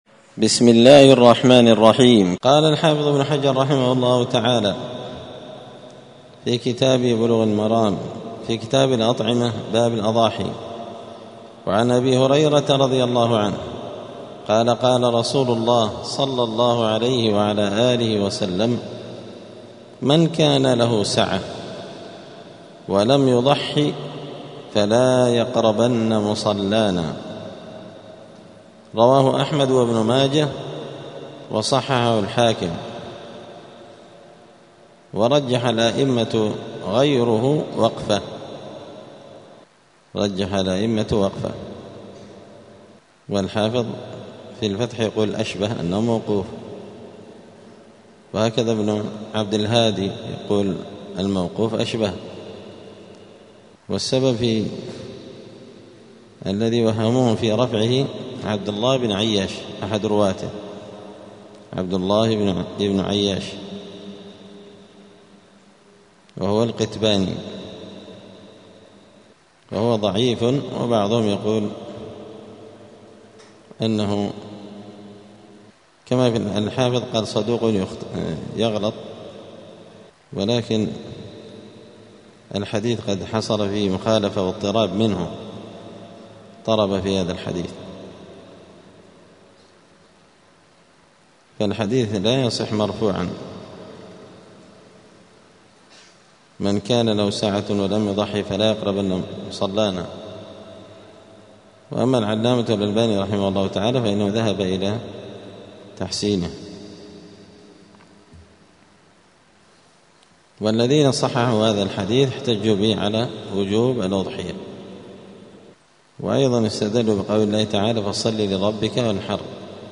*الدرس الثاني والعشرون (22) {شروط الأضحية وحكمها}*
دار الحديث السلفية بمسجد الفرقان قشن المهرة اليمن